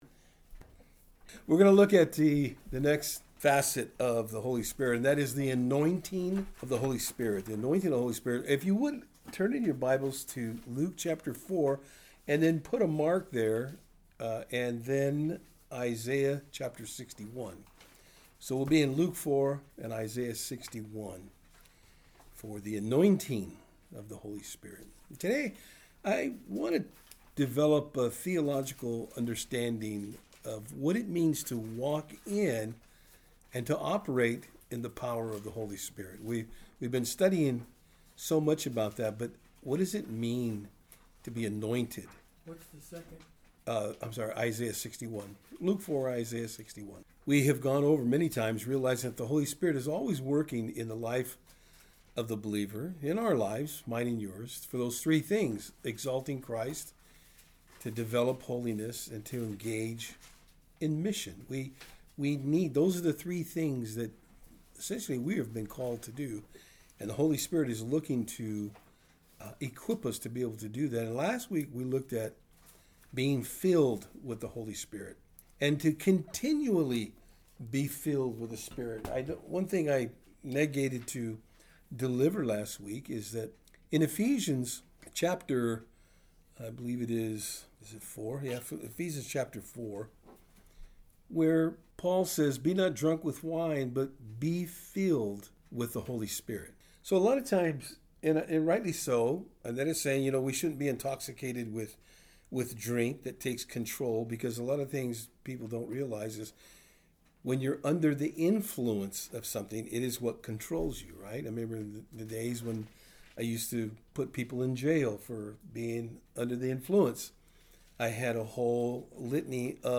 Luke 4 & Isaiah 61 Service Type: Thursday Afternoon In today’s study we will look at The Anointing of the Holy Spirit.